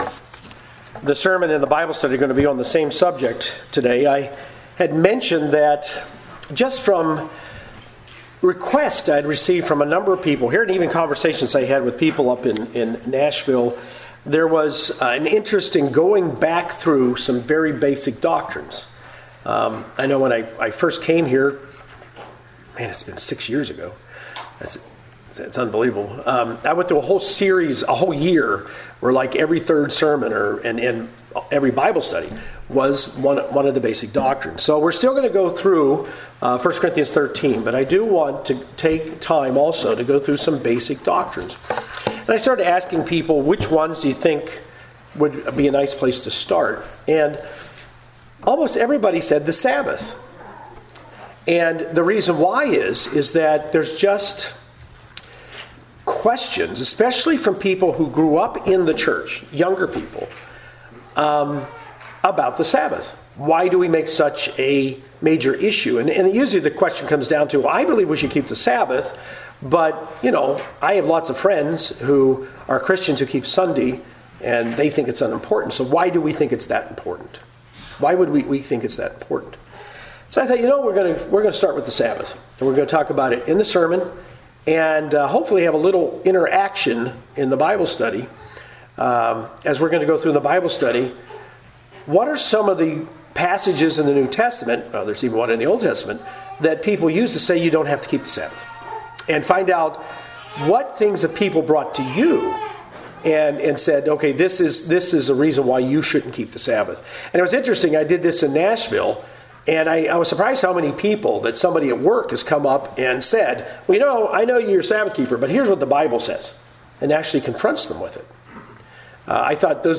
This sermon and corresponding Bible Study cover the basic doctrine on the 7th-day Sabbath.